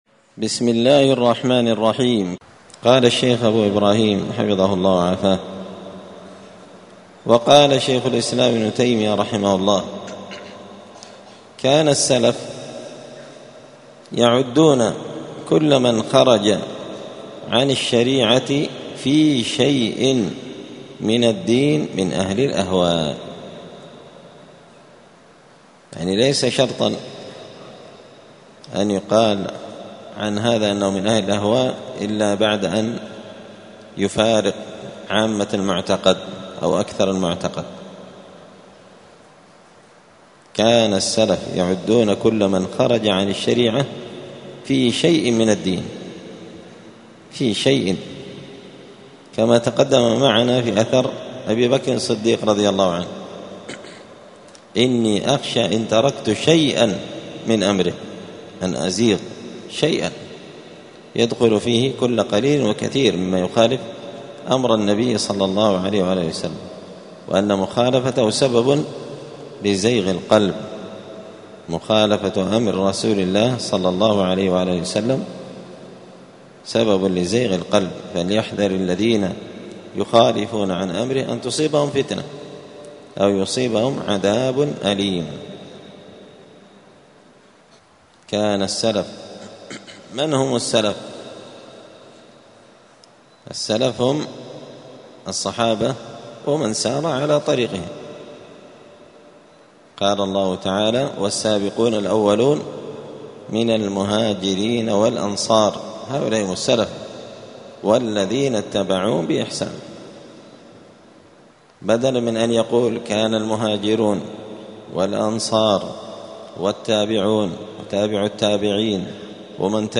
دار الحديث السلفية بمسجد الفرقان بقشن المهرة اليمن
52الدرس-الثاني-والخمسون-من-كتاب-الفواكه-الجنية.mp3